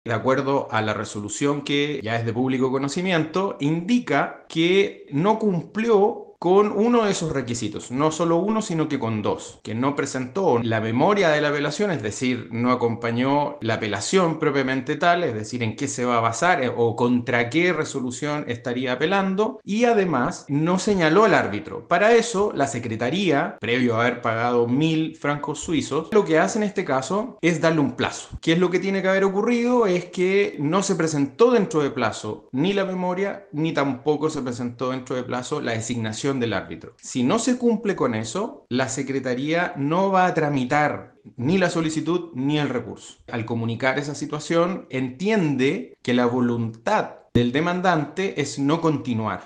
Abogado sobre caso de la U